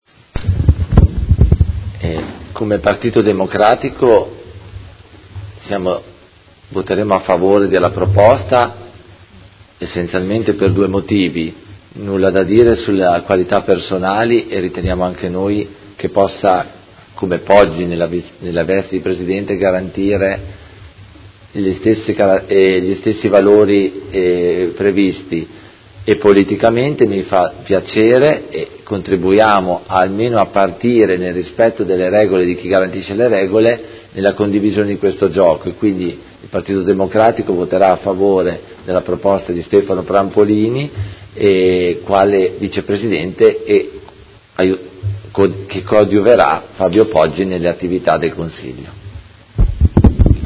Antonio Carpentieri — Sito Audio Consiglio Comunale